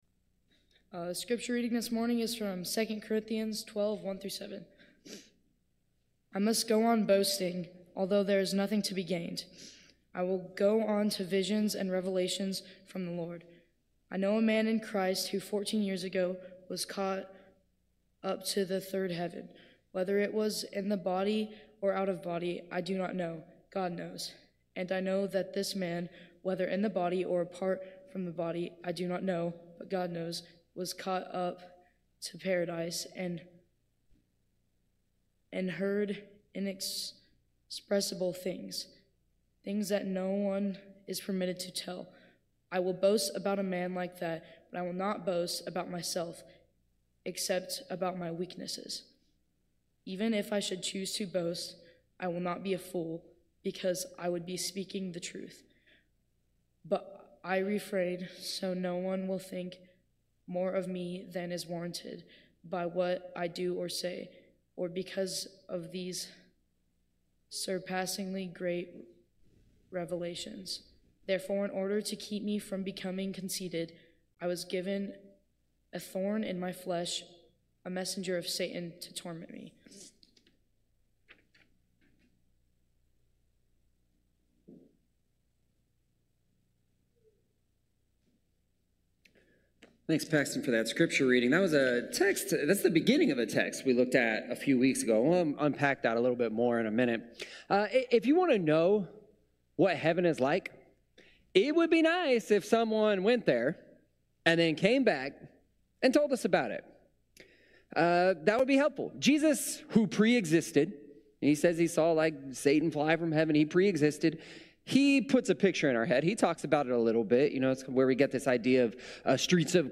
This week's bulletin - 7/28/2024 More from the series: Kid Questions ← Back to all sermons